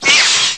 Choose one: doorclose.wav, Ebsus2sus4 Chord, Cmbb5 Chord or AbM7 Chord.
doorclose.wav